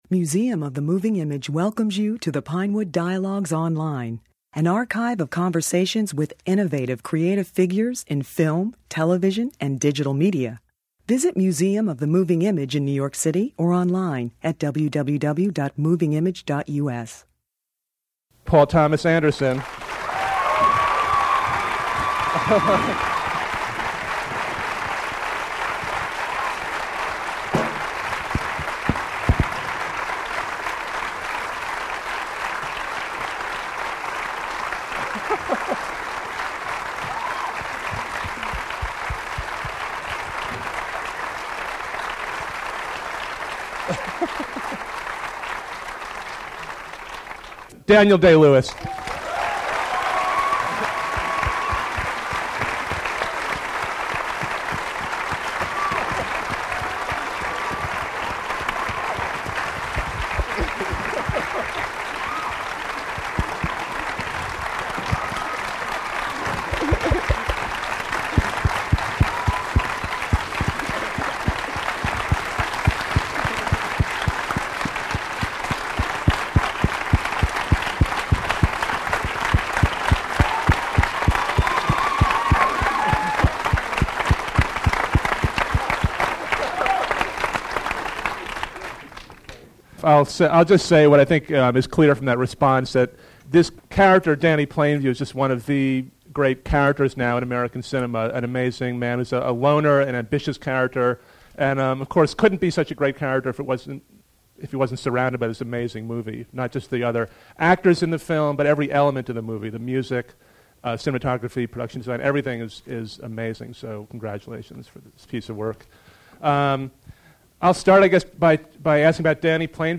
Daniel Day-Lewis's magnificent performance as the ambitious and ruthless oil tycoon Daniel Plainview is at the core of Paul Thomas Anderson's critically acclaimed movie There Will be Blood. In this discussion, which followed a Museum of the Moving Image preview screening of the film, the actor and director playfully and thoughtfully discussed their intense collaborative process.